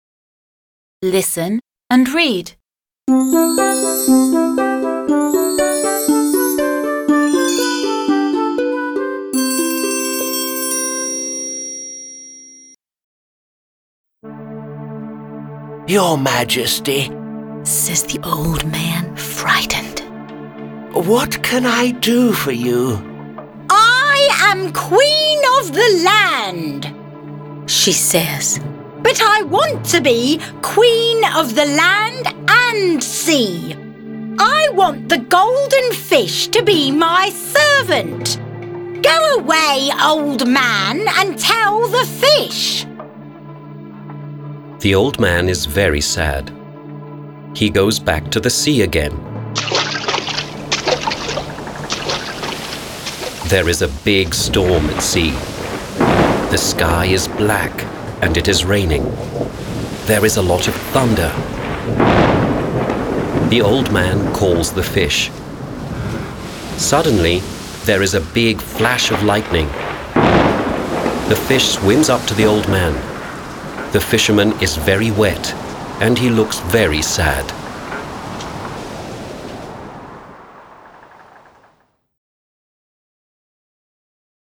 08-Story-p.-68.mp3